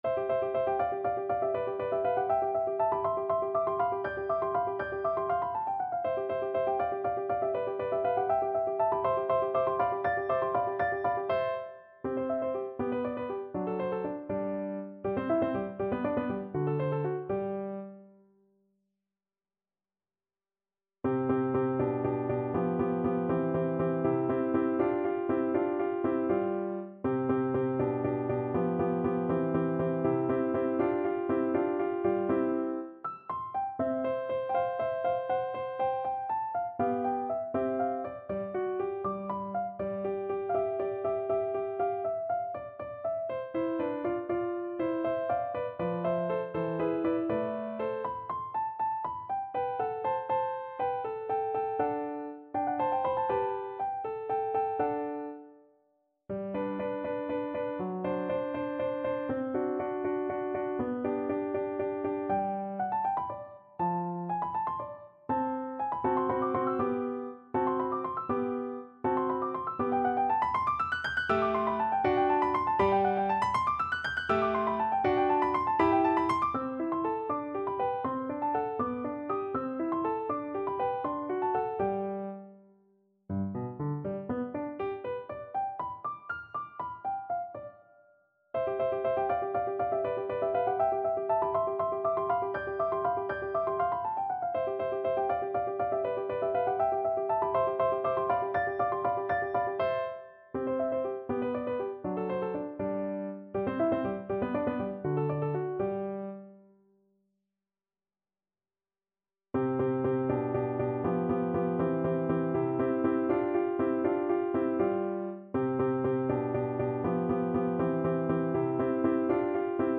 ~ = 100 Allegro (View more music marked Allegro)
6/8 (View more 6/8 Music)
Classical (View more Classical Flute Music)